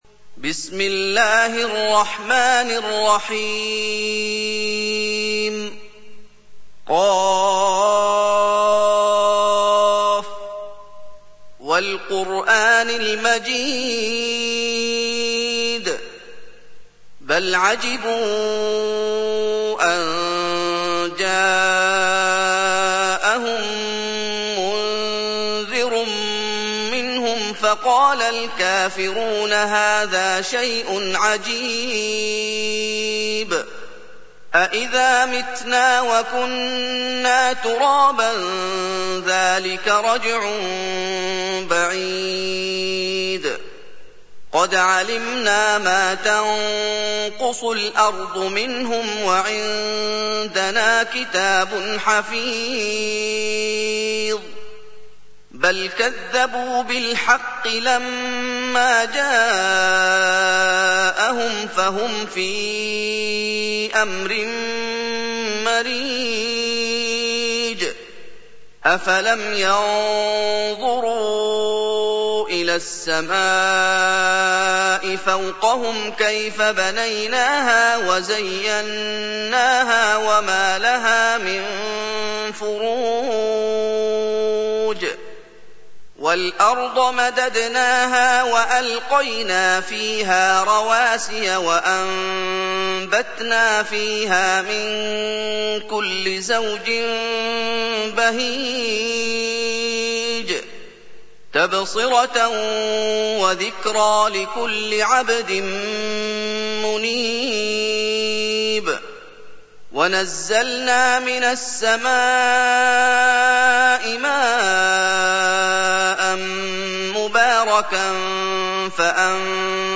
سُورَةُ ق بصوت الشيخ محمد ايوب